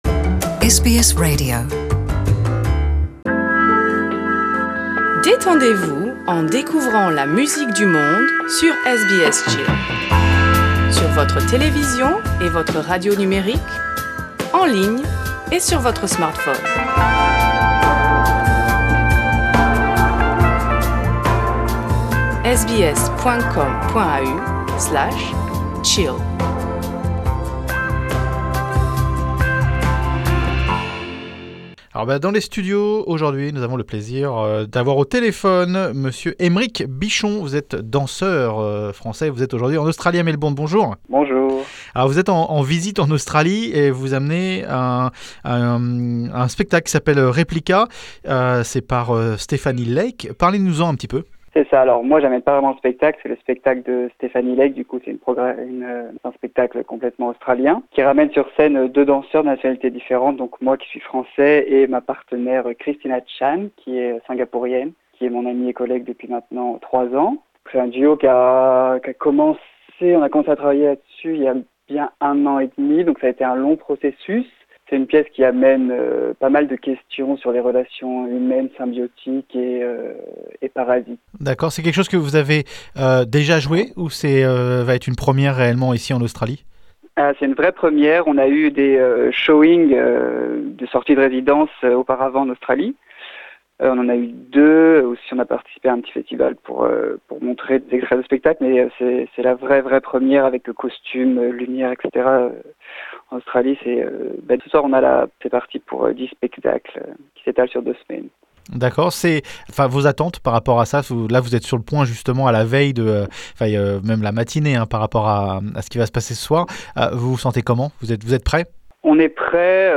Cette interview date d'Avril 2018.